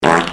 Fart Button Sound Effect Free Download
Fart Button